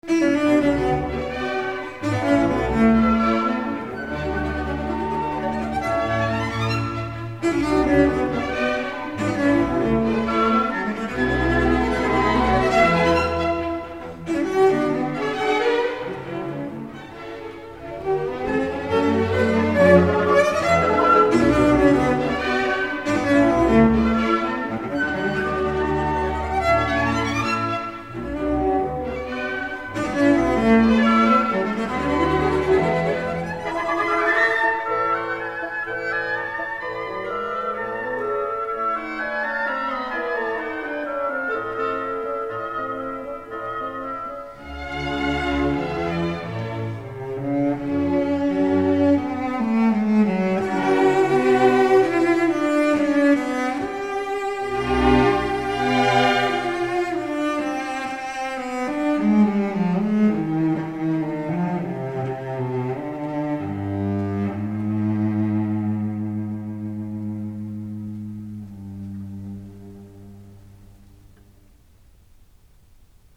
ДЛЯ ВИОЛОНЧЕЛИ С ОРКЕСТРОМ